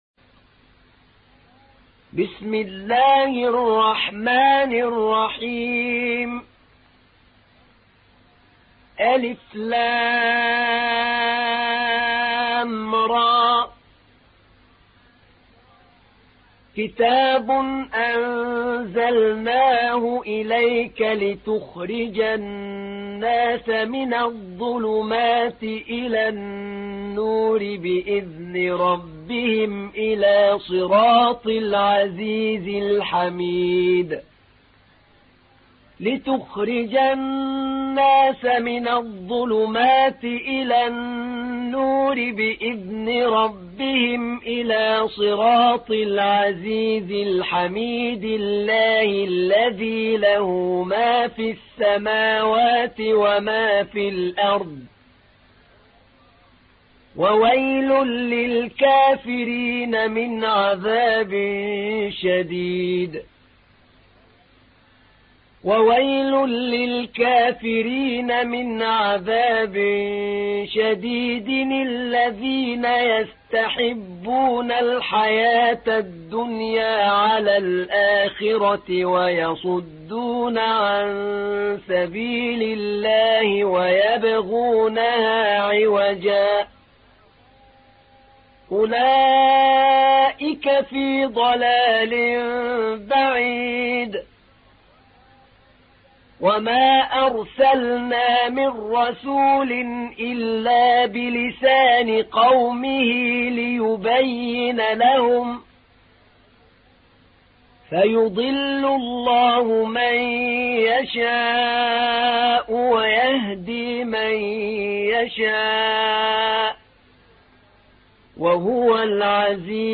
تحميل : 14. سورة إبراهيم / القارئ أحمد نعينع / القرآن الكريم / موقع يا حسين